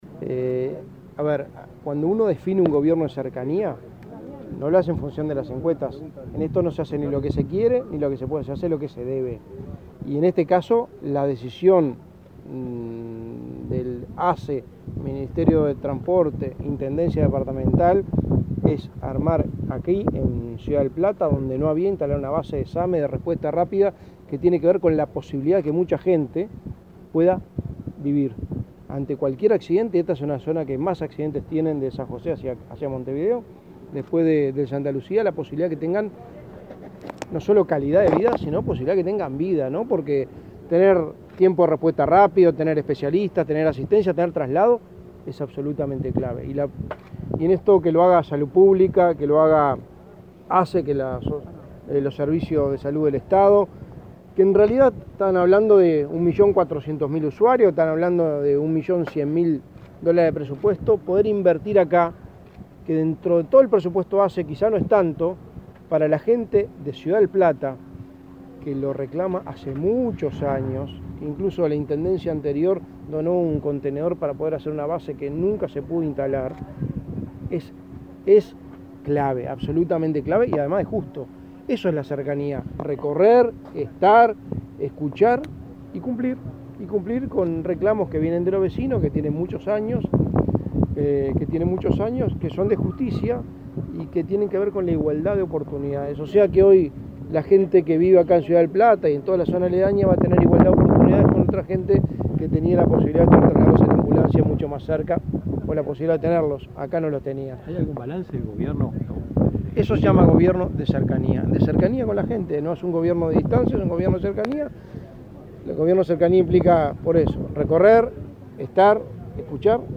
Declaraciones a la prensa del secretario de Presidencia de la República, Álvaro Delgado
El secretario de Presidencia, Álvaro Delgado, participó del acto y, luego, dialogó con la prensa.